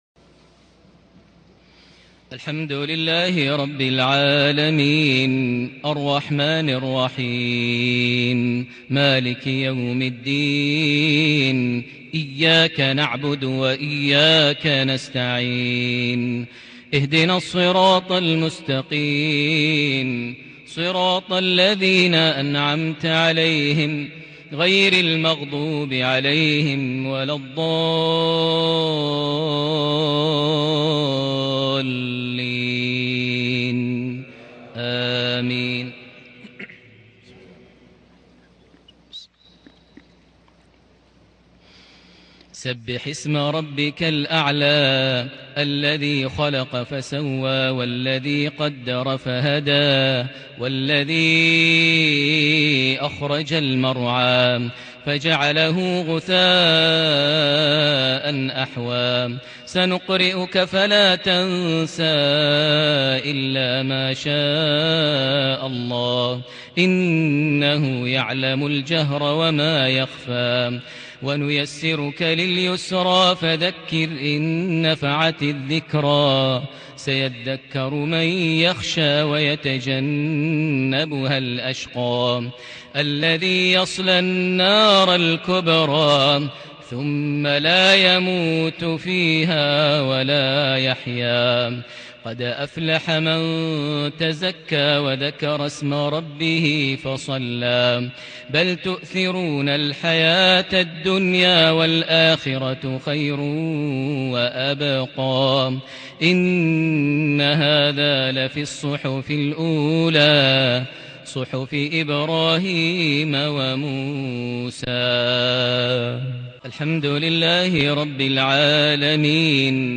صلاة الجمعة 28 محرم 1441هـ سورتي الأعلى والغاشية > 1441 هـ > الفروض - تلاوات ماهر المعيقلي